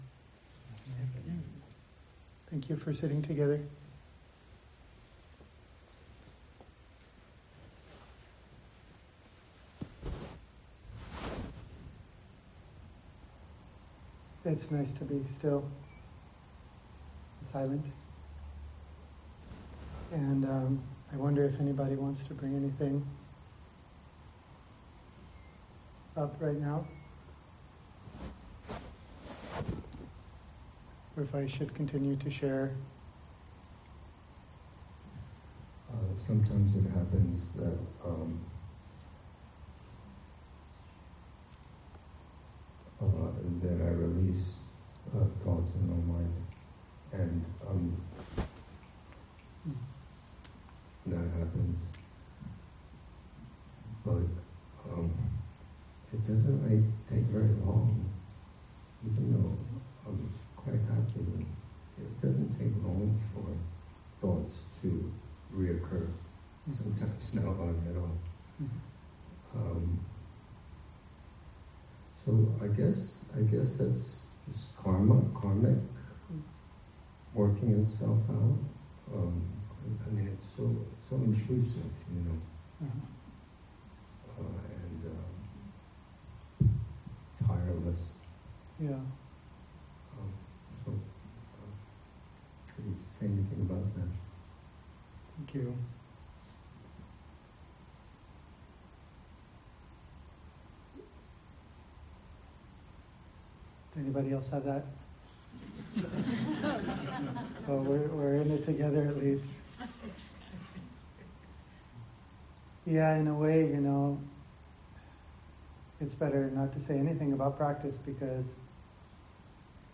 “Becoming Yourself” – Dharma Talk